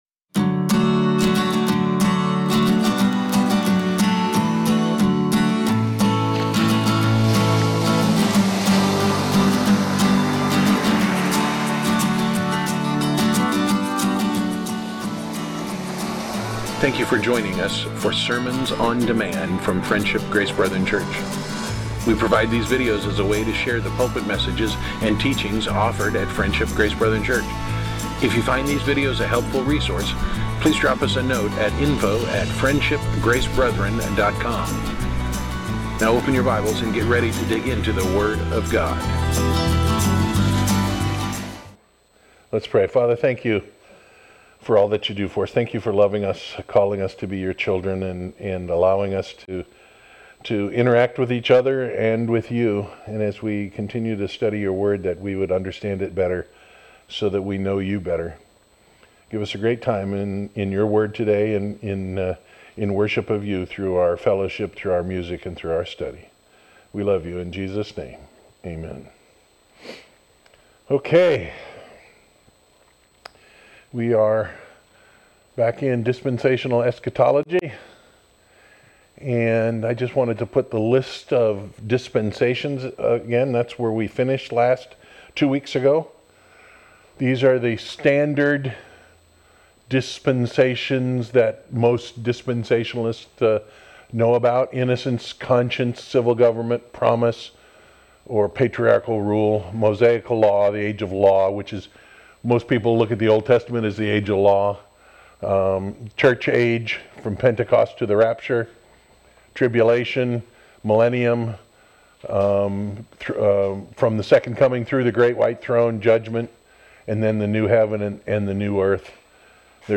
Series: Dispensational Eschatology, Sunday School